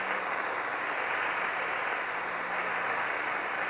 1 channel
static.mp3